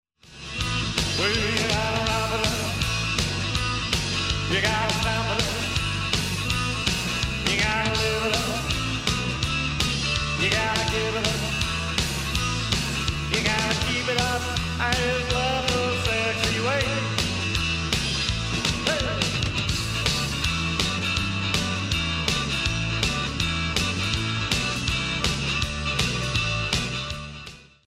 Rocking, cajun style of music
playing fiddle and lap steel guitar.